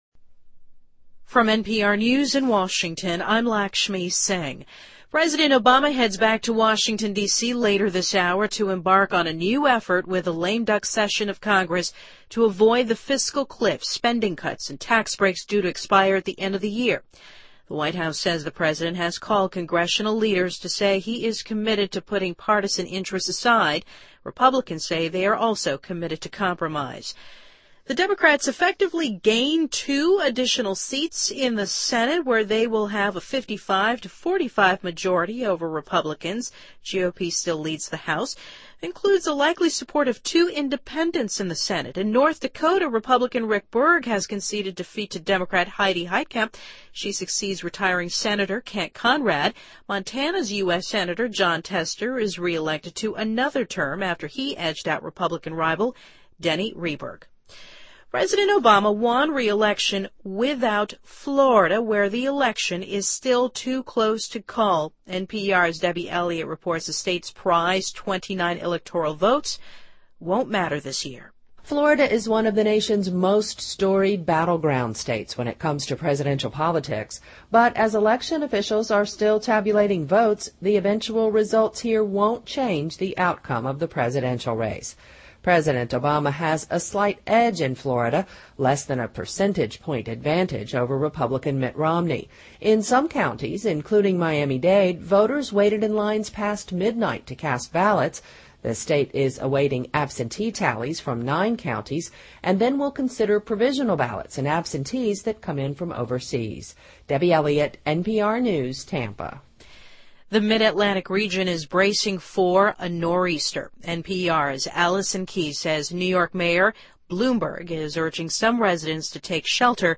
Date:2012-11-08Source:NPR Editor:NPR News